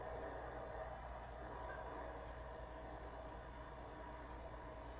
Kitchen » sovietKitchenRefrigerator
描述：Old soviet refrigerator "Бирюса" ("Birjusa") starts and rumble and stops.
标签： soviet USSR rumble refrigerator stop kitchen start
声道立体声